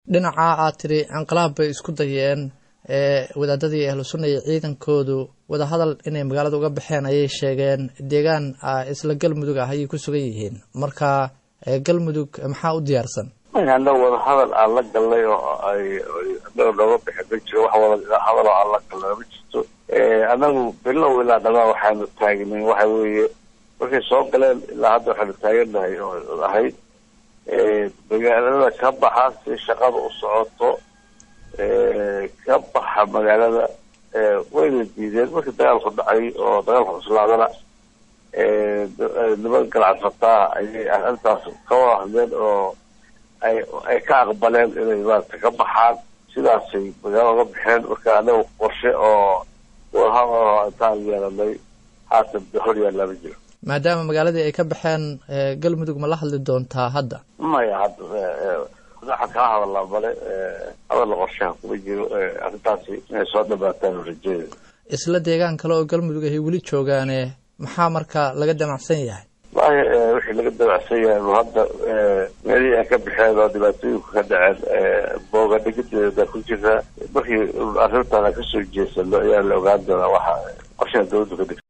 Wasiirka warfaafinta dowlad goboleedka Galmudug Axmad Shire Falagle oo la hadlay idaacadda Star Fm ayaa sheegay in aysan jirin wax wada hadal ah oo ay la yeesheen culimada Ahlusunna oo dagaal ay kula galeen Guriceel wuxuuna hoosta ka xarriiqay in aysan la hadli doonin
falagle-wasiirka-warfaafinta-Galmudug.mp3